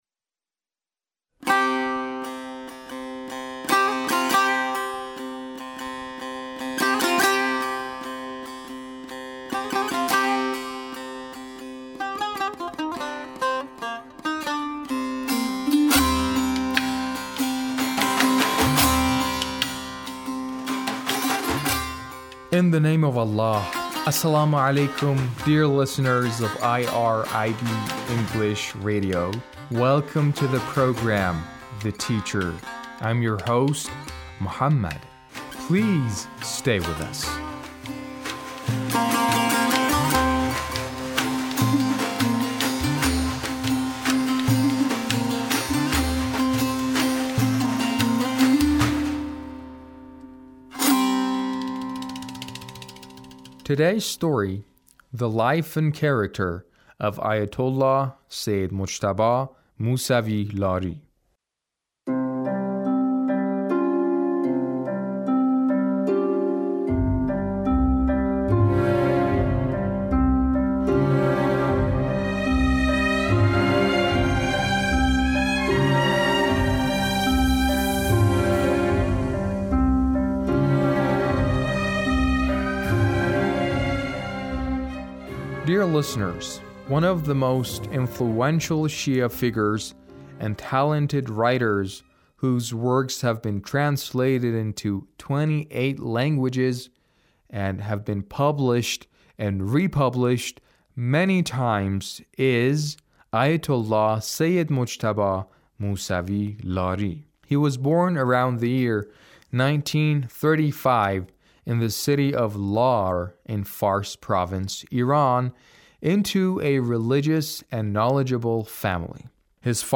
A radio documentary on the life of Ayatullah Sayyid Mujtaba Musavi Lari - 2